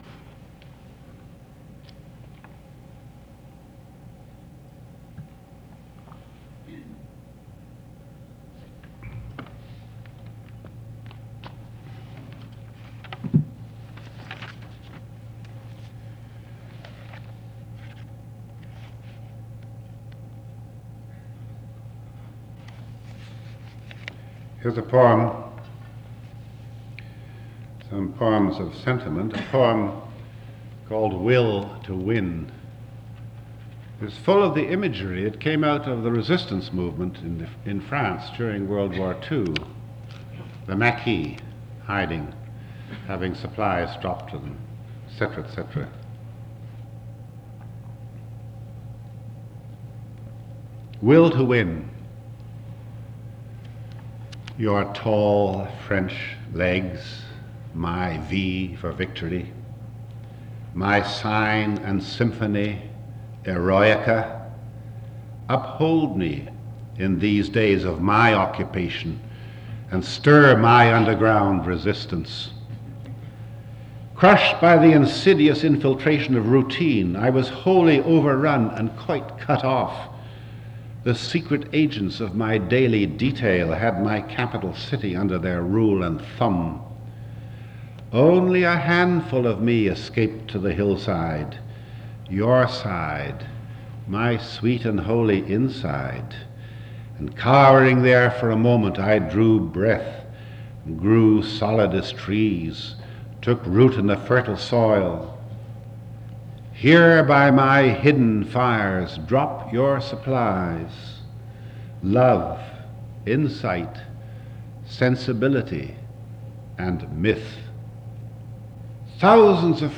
Series Title: The Poetry Series
Production Context: Documentary recording
Role: Author, Performer